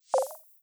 Modern UI SFX / SlidesAndTransitions